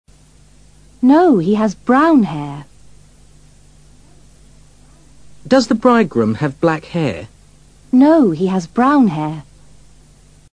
Cuando deseamos enfatizar una palabra en una oración, decimos esa palabra en voz más elevada que lo normal (more loudly) y también lo hacemos utilizando un tono más alto (a higher pitch).